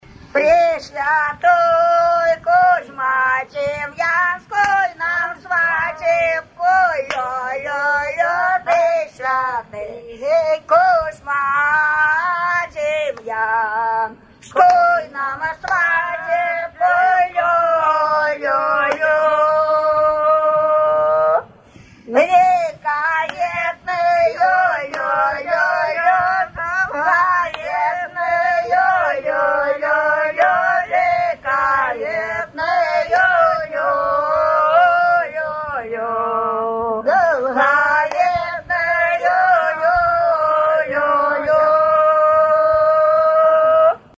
Свадебные обрядовые песни в традиции верховья Ловати